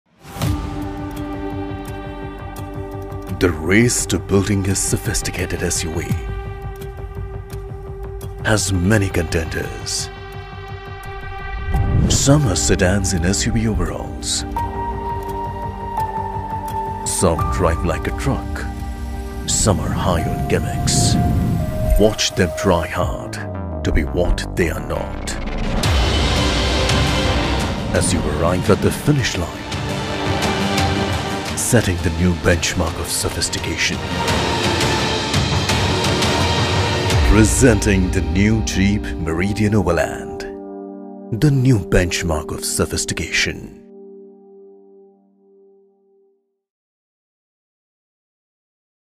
Whenever you need a deep, baritone, sonorous voice to show your product to the world, you can get all of it from my voice.
English Promo  (Genre - Stylish,Grand,Heavy).mp3